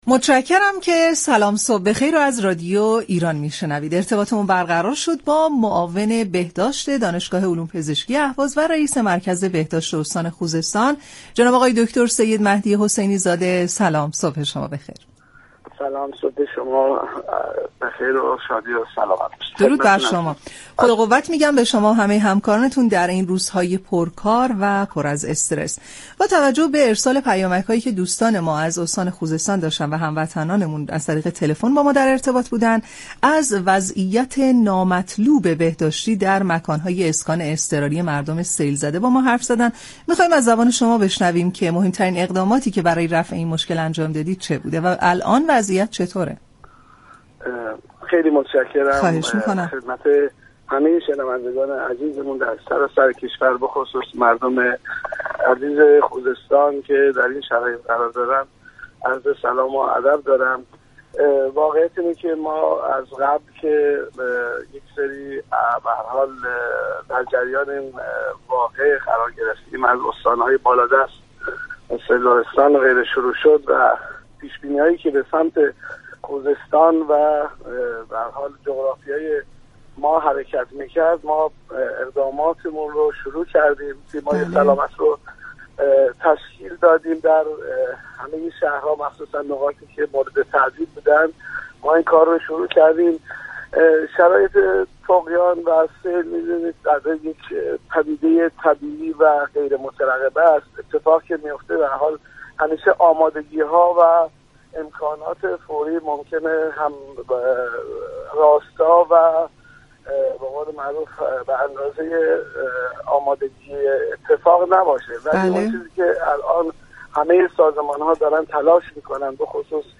دكتر سیدمهدی حسینی زاده معاون بهداشت دانشگاه علوم پزشكی اهواز و رئیس مركز بهداشت استان خوزستان در برنامه سلام صبح بخیر رادیو ایران گفت : ممكن است كاستی هایی باشد اما از همه دستگاه ها پیگیر هستیم